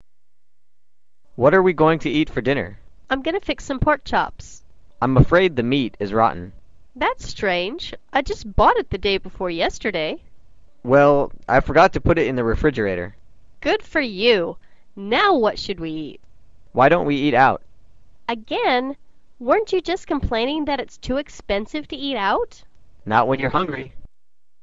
5. انتبه لنطق rotten حيث الـ o لا تُنطق كأنها تُنطق rottn
وتذكر أن سرعة المحادثة عادية.